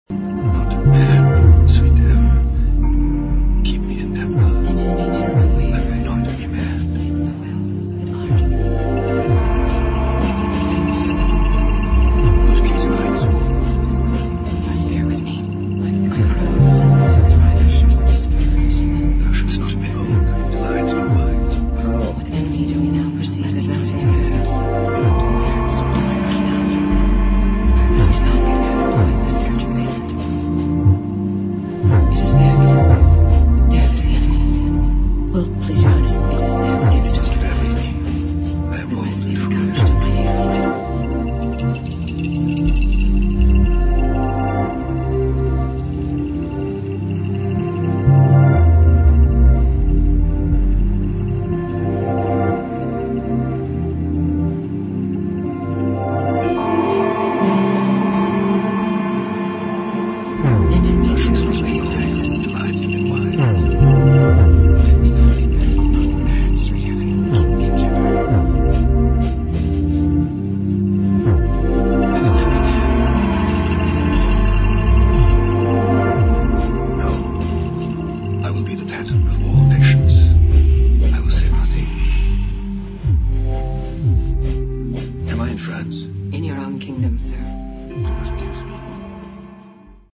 [ ELECTRONIC / EXPERIMENTAL / AMBIENT ]